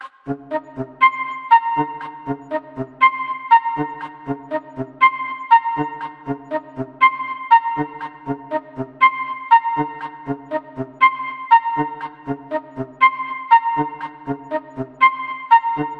电子配音
描述：用lmms（linux multimedia studio）创建的，带有一些音高弯曲和ladspa延迟
Tag: 140 节拍 回响贝斯 LINUX LMMS 循环 节奏 圈套